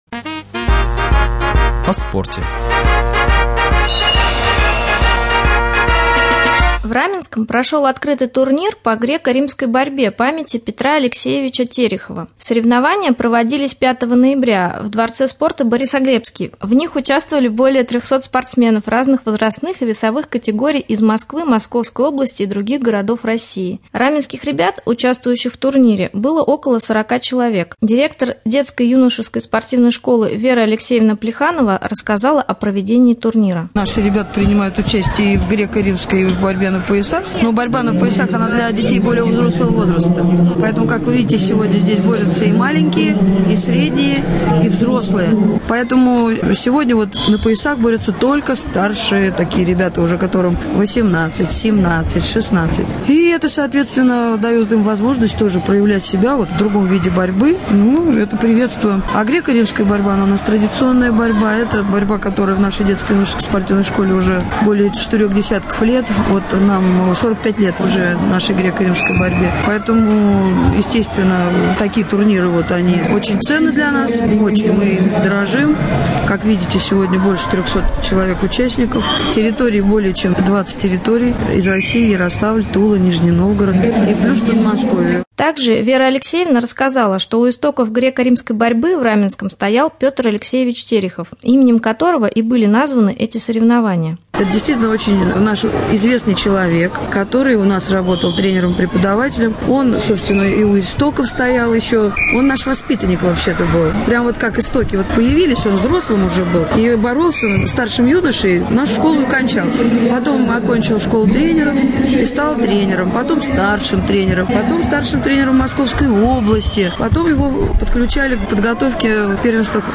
Во Дворце спорта «Борисоглебский» прошел турнир по греко-римской борьбе. Репортаж